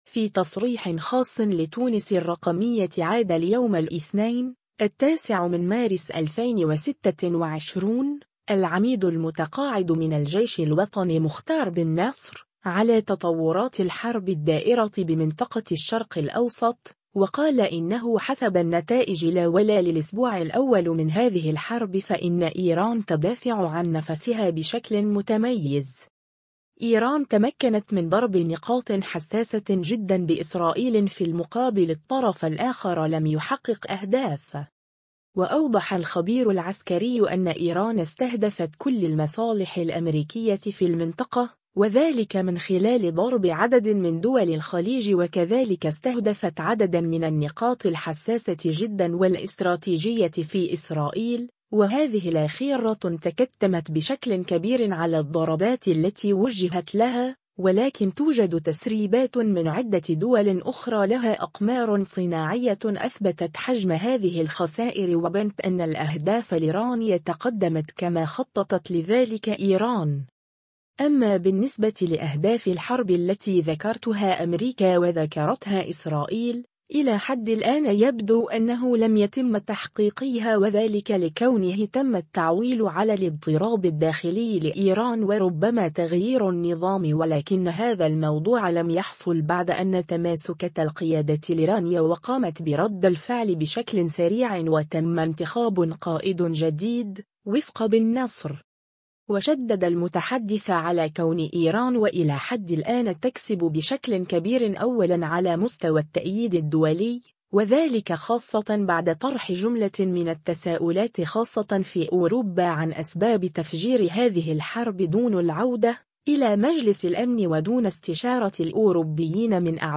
في تصريح خاص لتونس الرّقمية